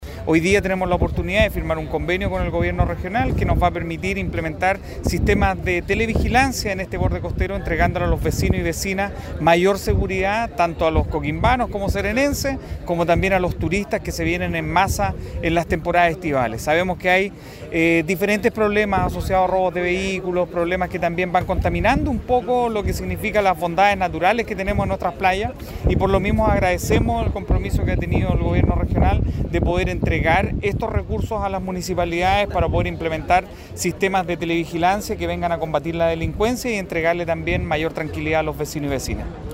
ALCALDE-DE-COQUIMBO-ALI-MANOUVHEHRI.mp3